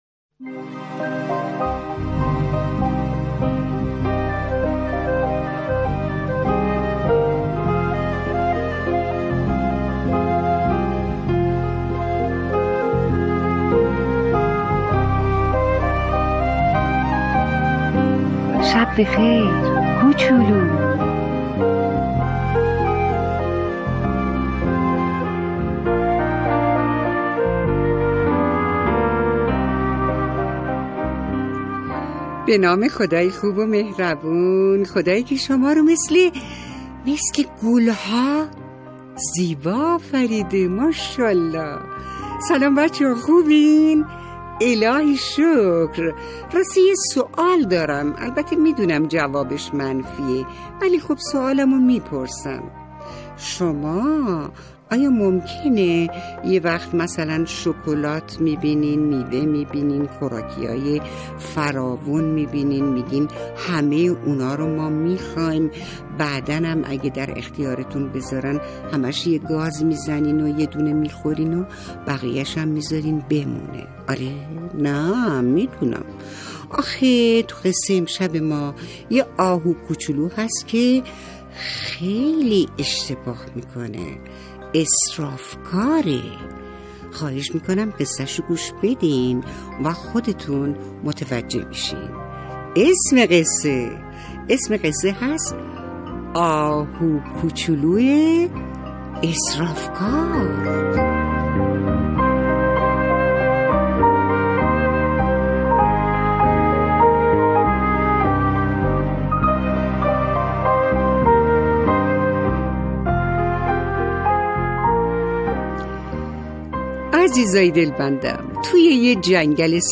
قصه صوتی کودکان دیدگاه شما 1,492 بازدید